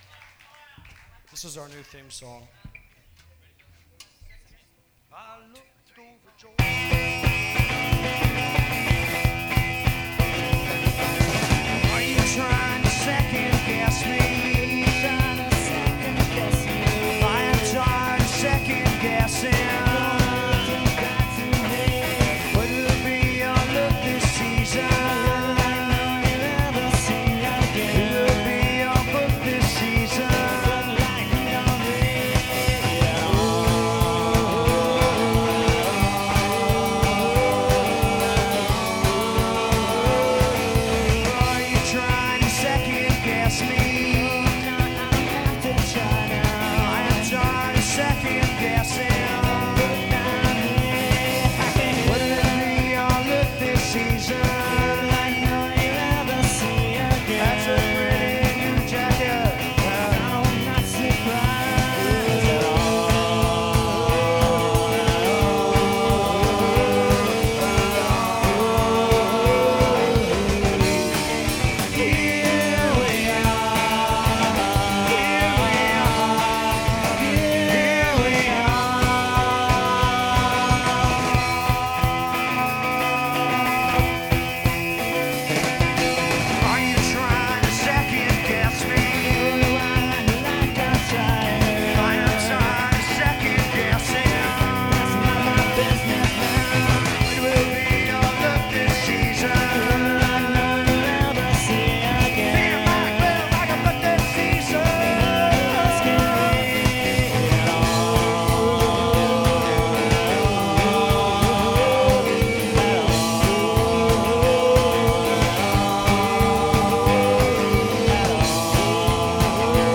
This review is on the soundboard show.